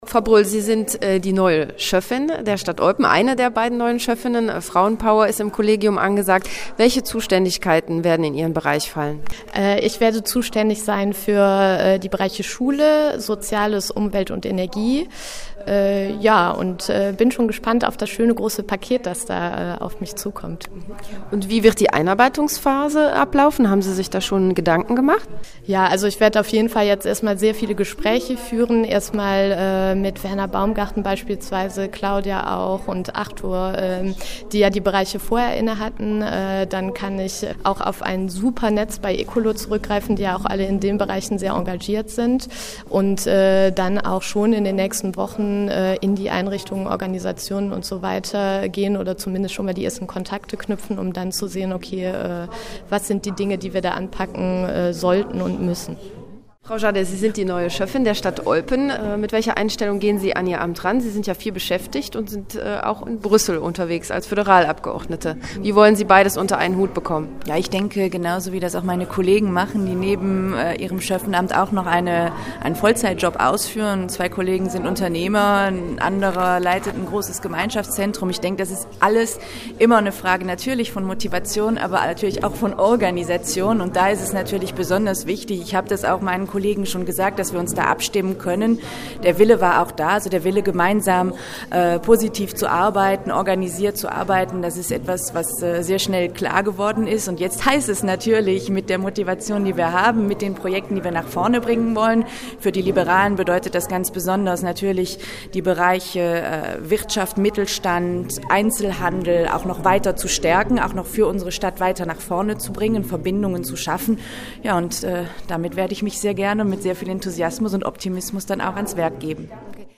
Hier kommen die beiden “Neuen” zu Wort: